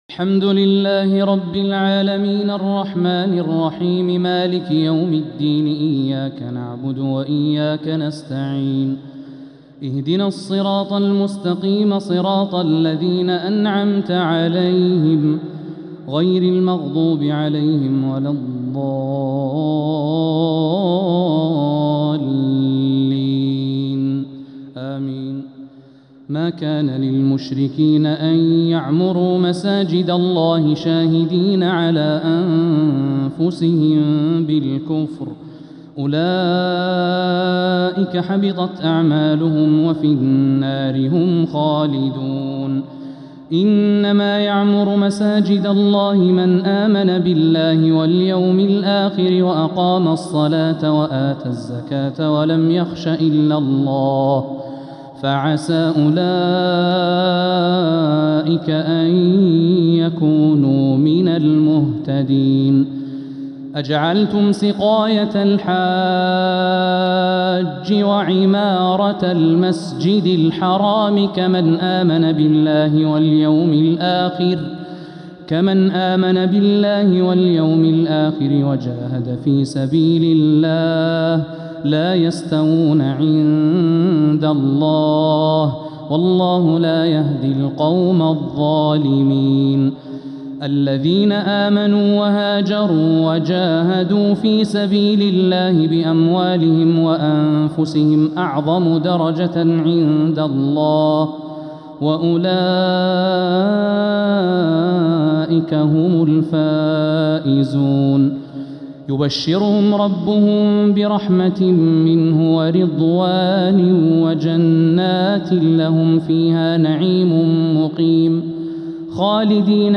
تراويح ليلة 13 رمضان 1447هـ من سورة التوبة (17-39) | Taraweeh 13th niqht Surat At-Tawba 1447H > تراويح الحرم المكي عام 1447 🕋 > التراويح - تلاوات الحرمين